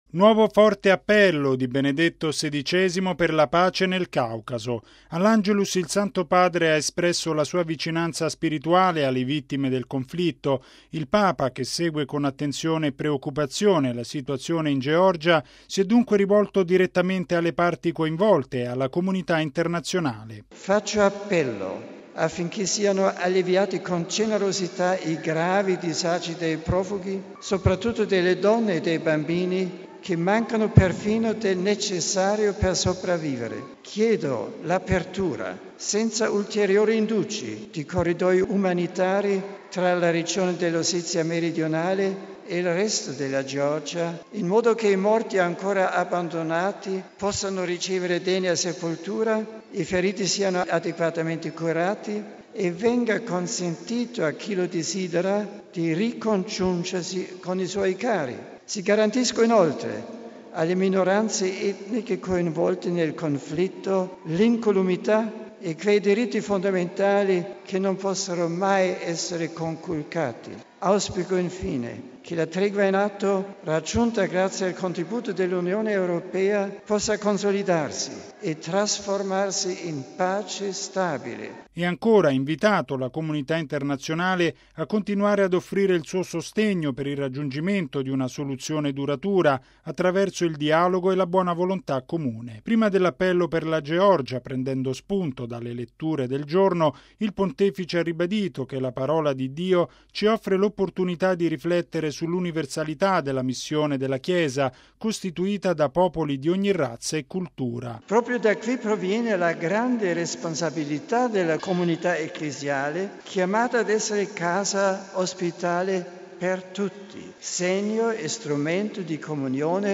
◊   Aprire senza indugi i corridoi umanitari per portare aiuto alle popolazioni in Georgia e Ossezia: è l’accorato appello di Benedetto XVI, levato stamani all’Angelus al Palazzo Apostolico di Castel Gandolfo.